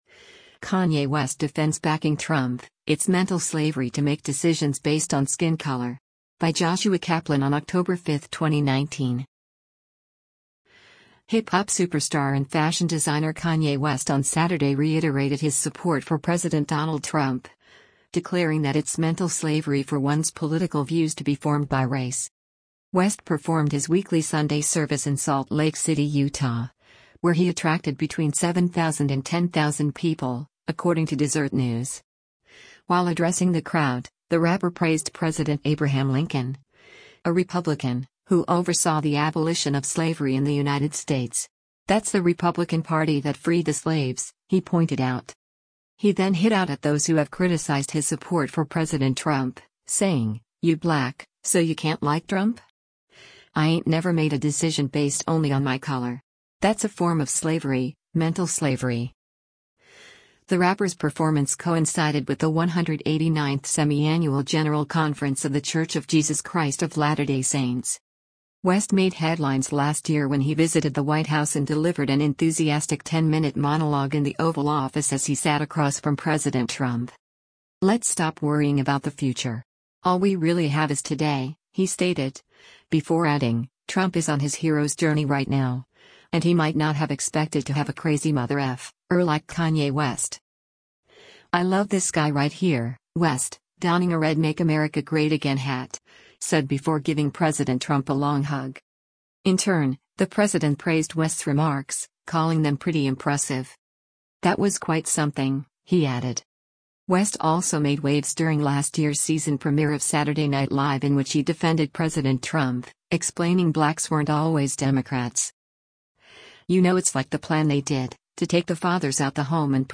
West performed his weekly Sunday Service in Salt Lake City, Utah, where he attracted between 7,000 and 10,000 people, according to Desert News.